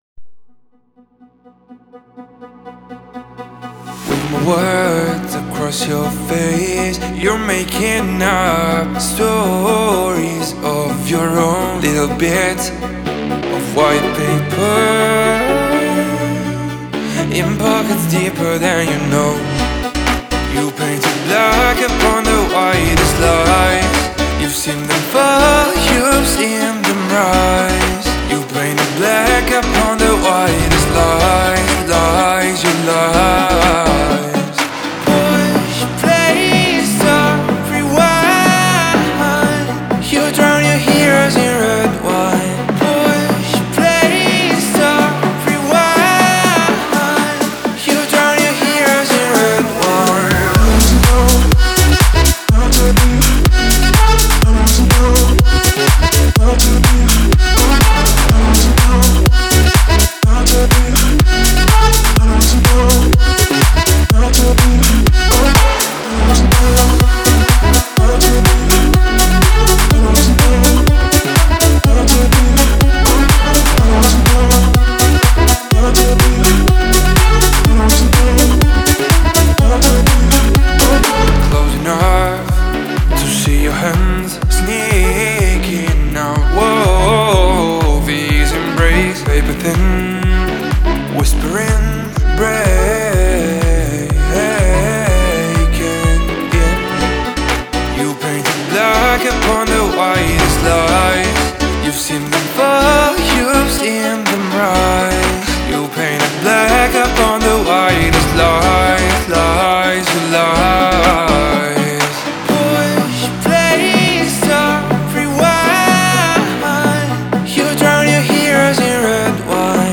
это энергичный трек в жанре поп-рок